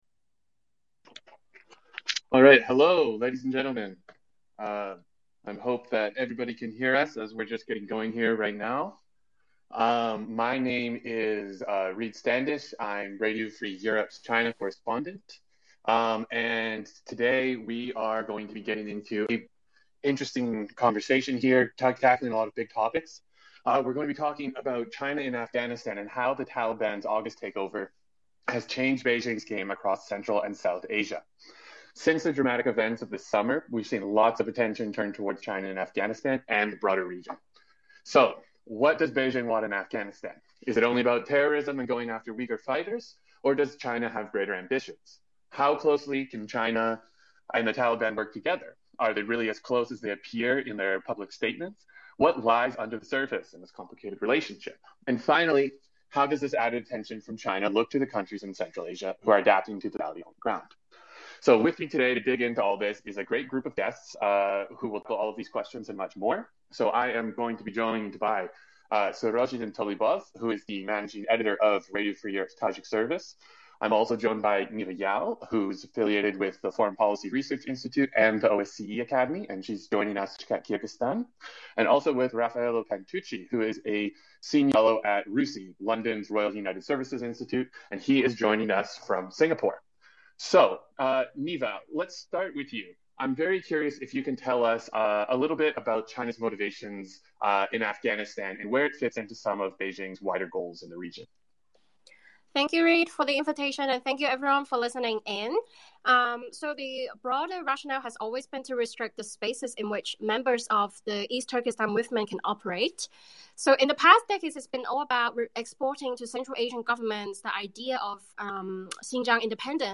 Twitter Spaces: A Talk About How The Taliban Takeover Changes China's Central Asia Strategy